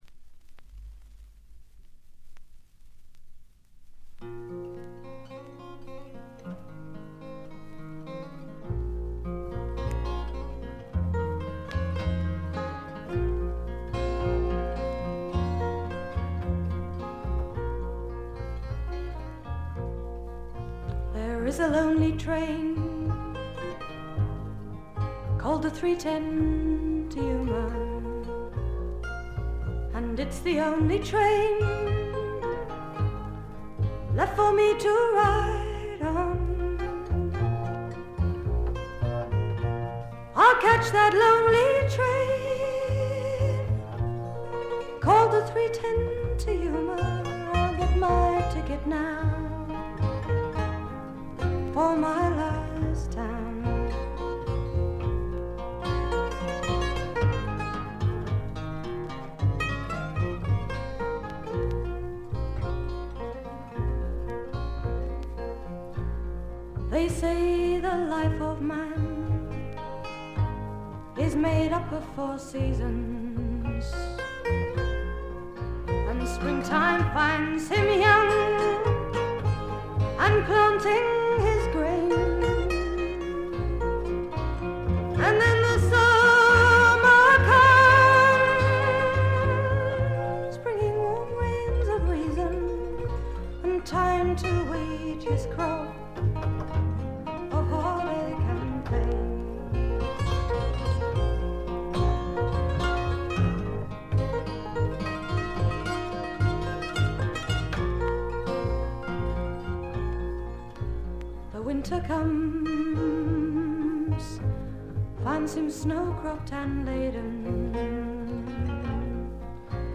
ホーム > レコード：英国 SSW / フォークロック
試聴曲は現品からの取り込み音源です。